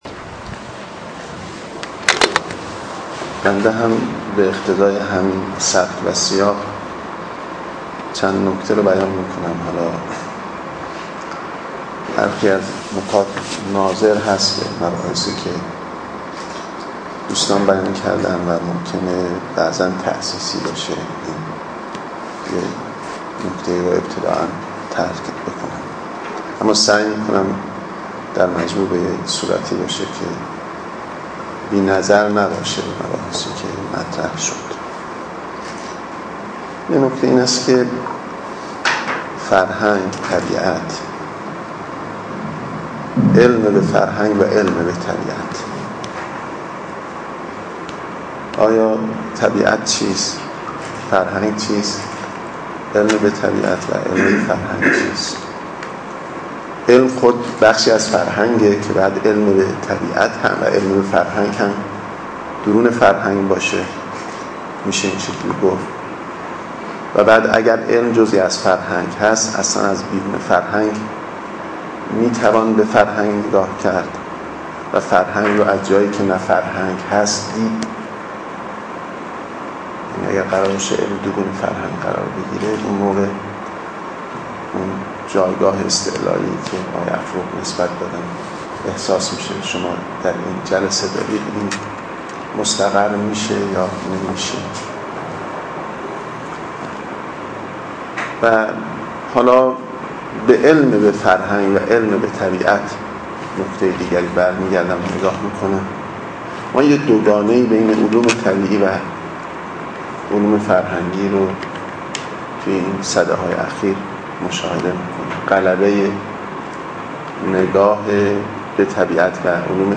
سخنرانی
در ششمین نشست از نشست‌های خانه اندیشمندان علوم انسانی با موضوع «فلسفه وفرهنگ» در تاریخ سی‌ام اردیبهشت ماه است.